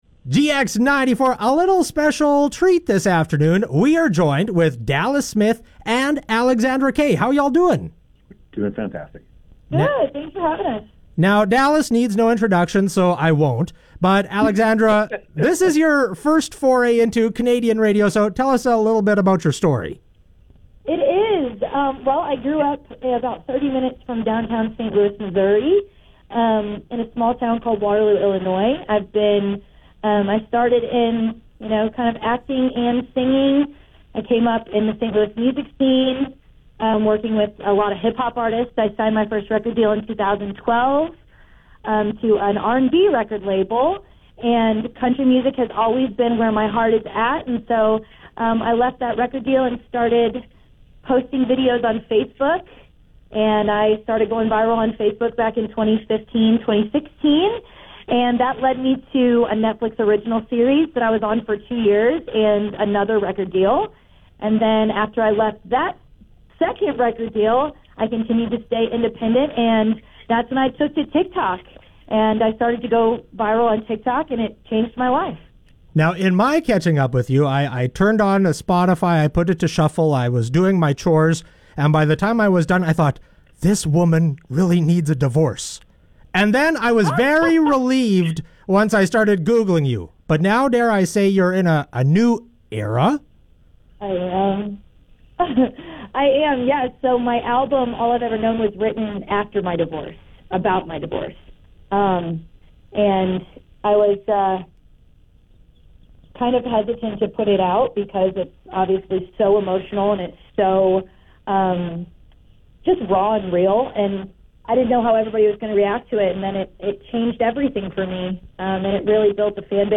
Interview with Dallas Smith and Alexandra Kay